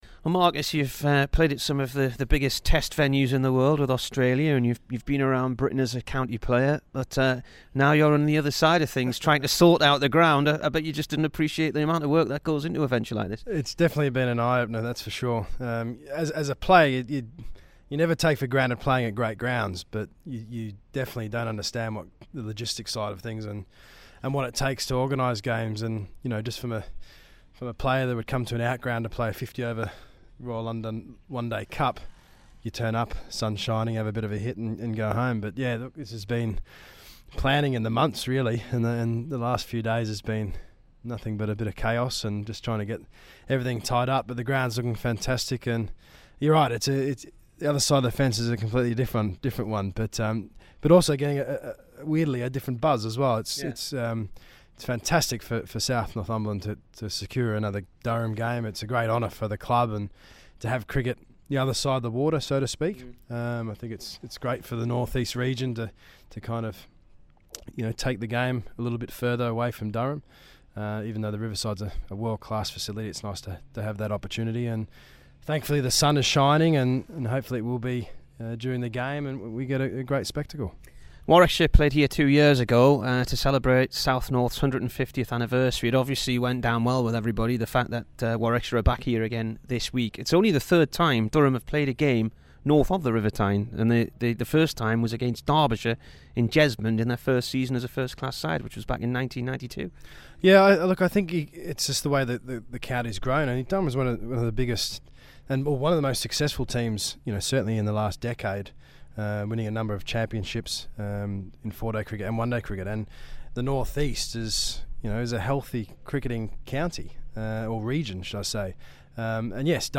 MARCUS NORTH INT